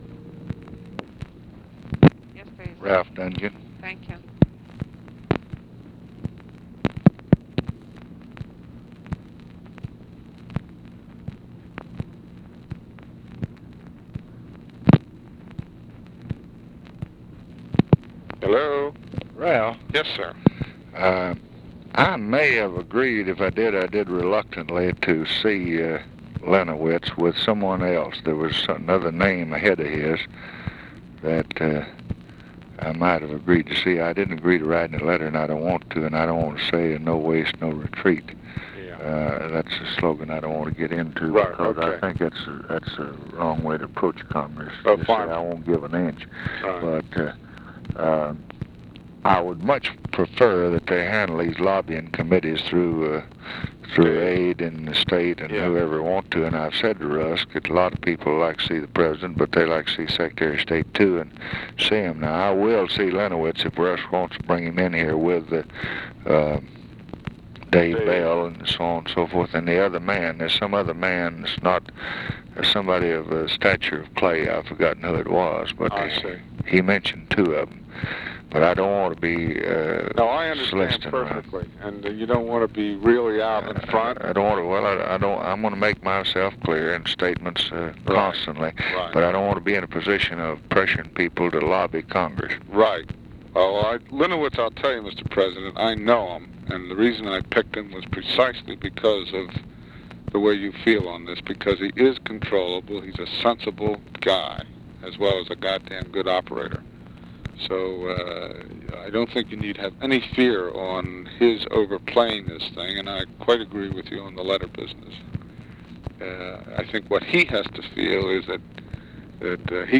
Conversation with RALPH DUNGAN, April 13, 1964
Secret White House Tapes